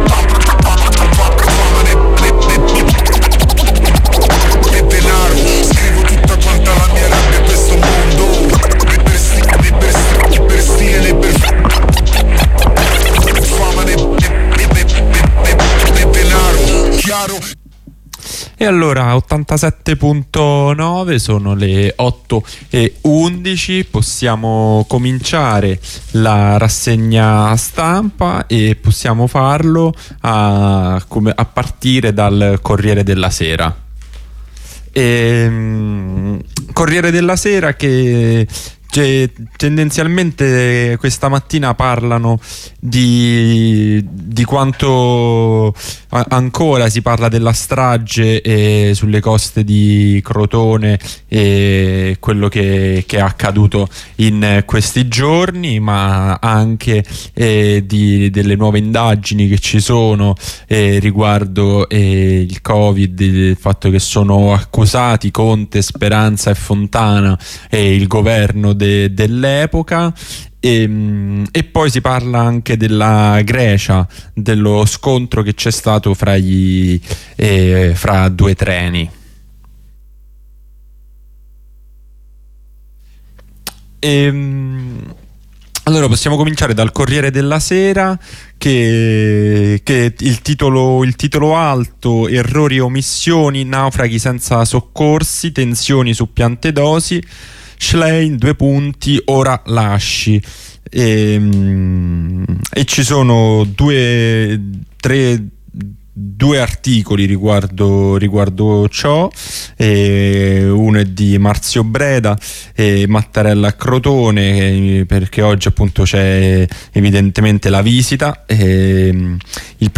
Rassegna Stampa di Giovedì 2 Marzo
Letture e commenti delle testate giornalistiche di oggi giovedì 2 marzo 2023